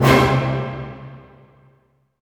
Index of /90_sSampleCDs/Roland LCDP08 Symphony Orchestra/HIT_Dynamic Orch/HIT_Orch Hit Maj
HIT ORCHM01R.wav